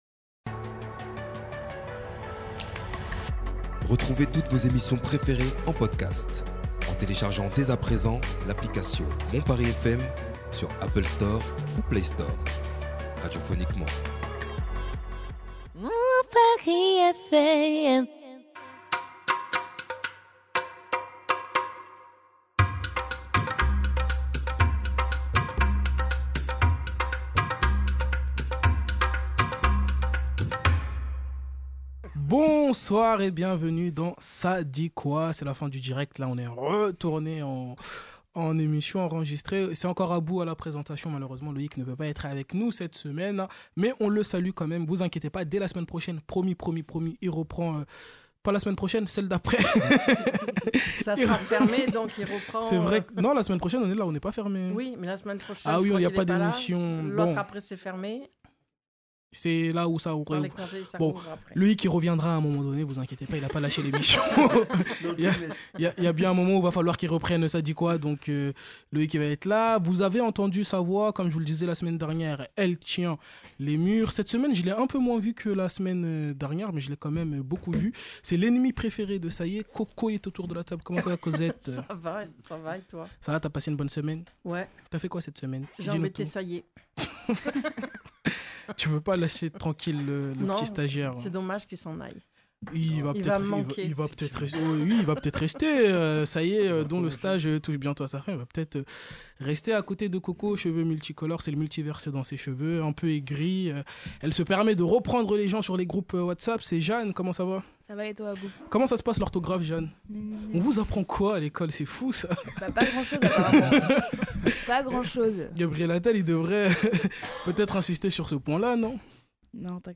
Débat de la semaine